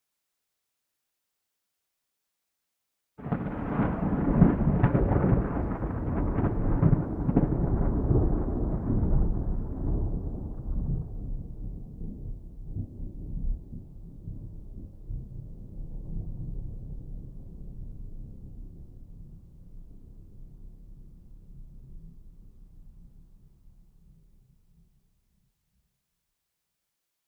thunderfar_4.ogg